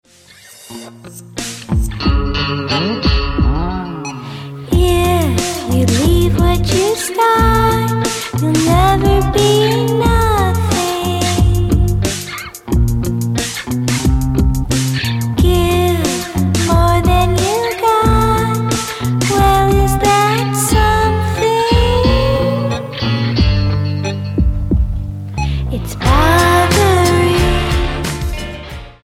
STYLE: Rock
uplifting music